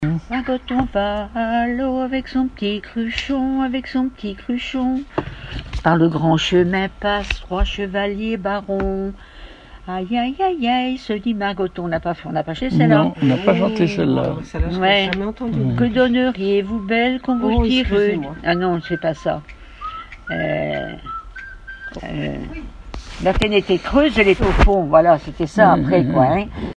Genre laisse
Témoignages et chansons
Catégorie Pièce musicale inédite